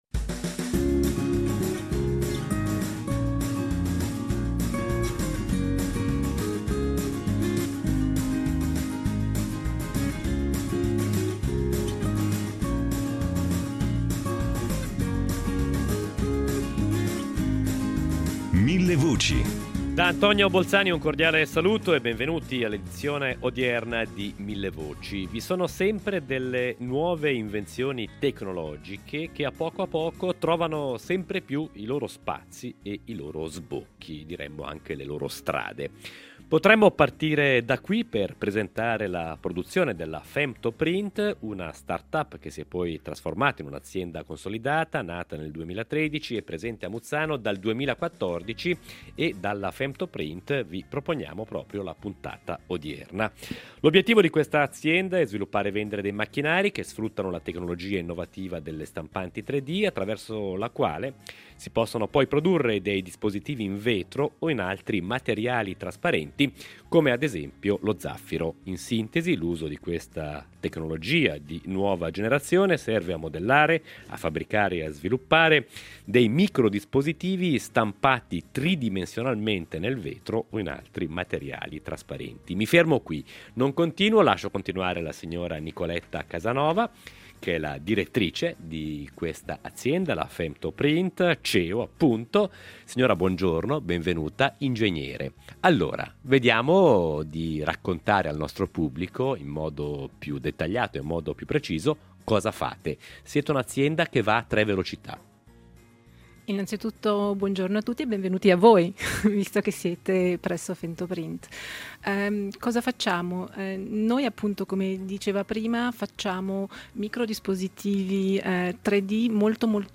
In diretta dalla Femtoprint di Muzzano